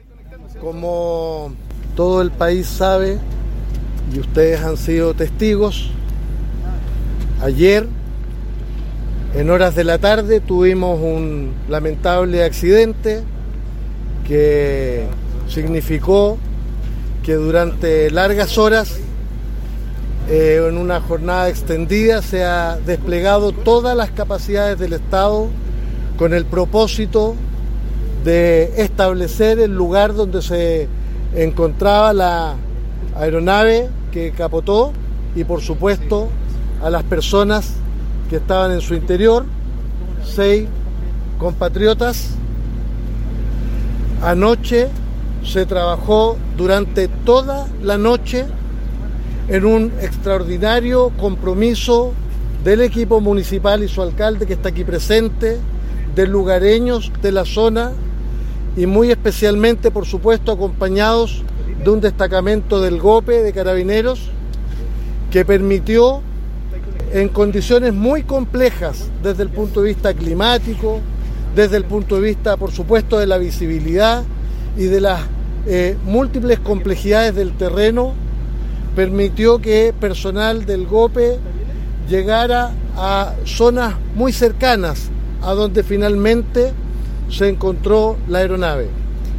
El delegado presidencial regional metropolitano, Gonzalo Durán, destacó el amplio despliegue de recursos y equipos durante toda la tarde-noche del miércoles, y parte del día jueves.